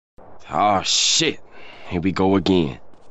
Ah shit, here we go again - Meme Effect Sound